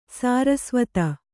♪ sārasvata